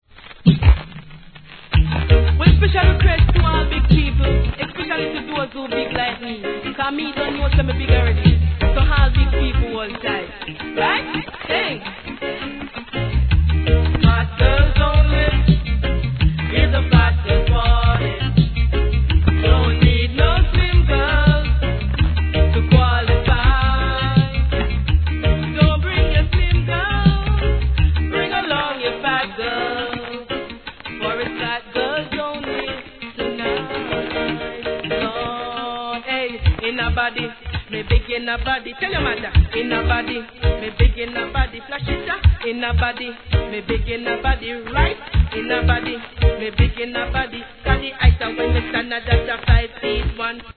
序盤に周期的なノイズ
REGGAE